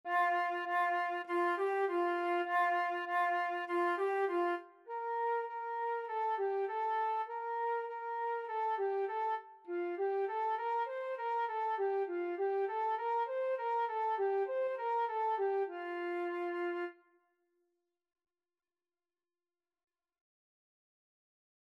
2/4 (View more 2/4 Music)
F5-C6
Beginners Level: Recommended for Beginners
Flute  (View more Beginners Flute Music)
Classical (View more Classical Flute Music)